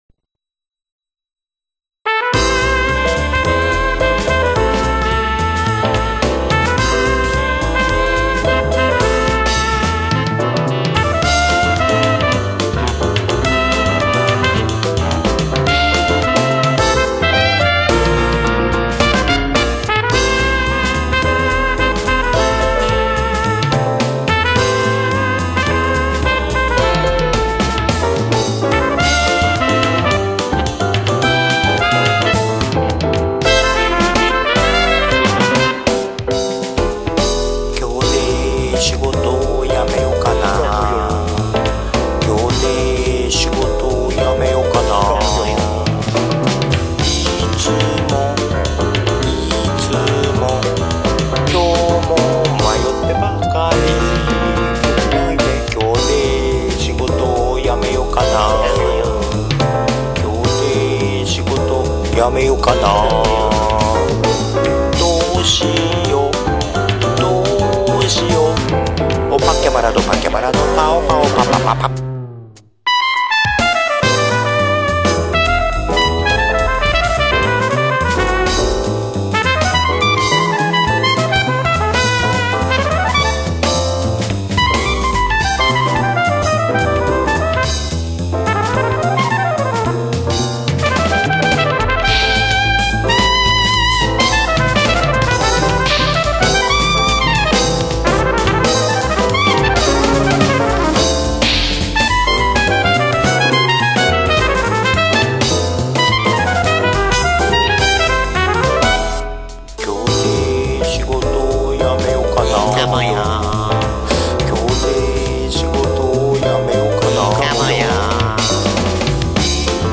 なんせこちとらマウス入力だから、フツーに作るとテンポも音程もタイミングもカンペキになっちゃうわけで、
なのでそんなにヘタな演奏にはできなかった。